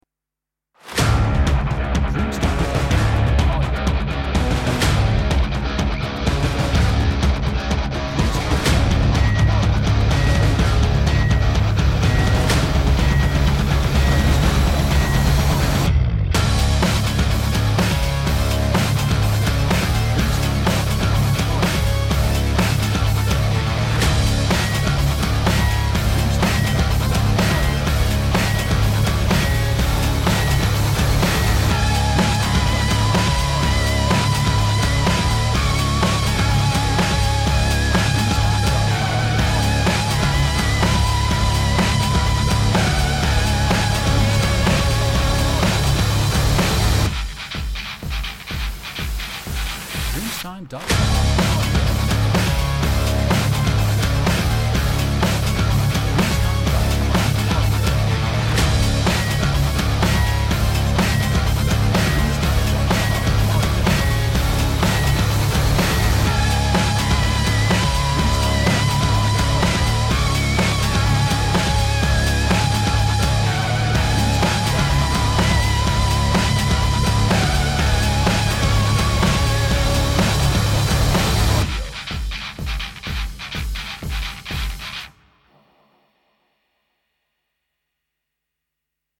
Action Electronic Metal